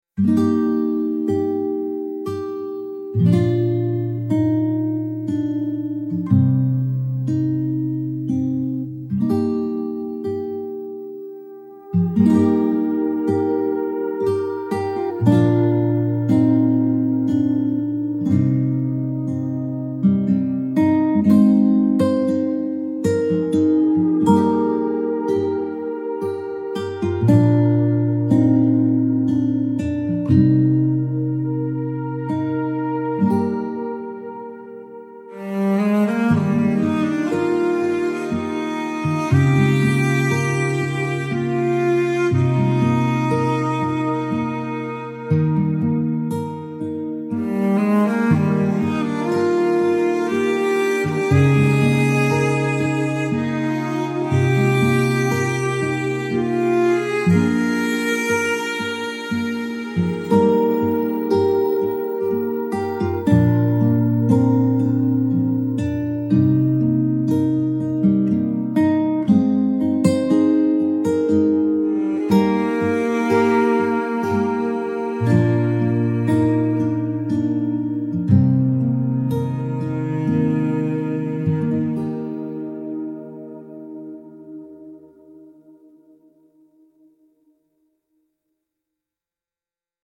intimate fingerpicked acoustic guitar with soft cello and ambient warmth